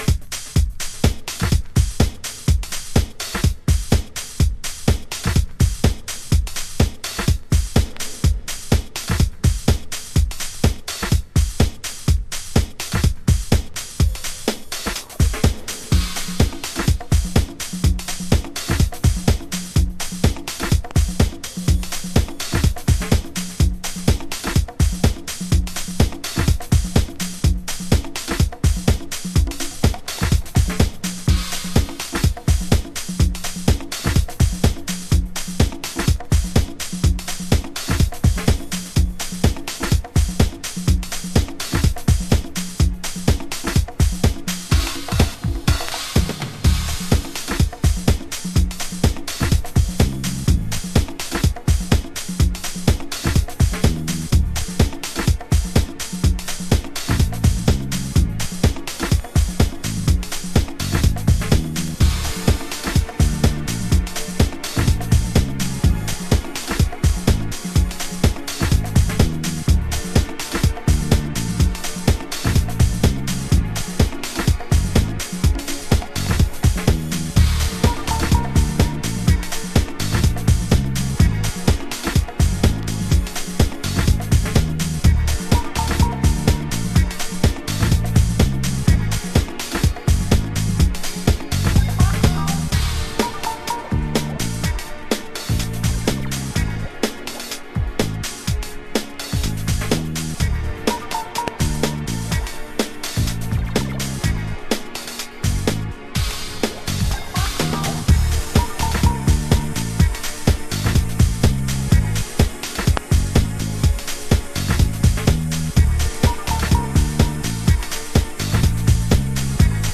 Early House / 90's Techno
ハードコアな現場を潜り抜けてきた多幸感あふれるディープハウス。胸がシャキシャキする。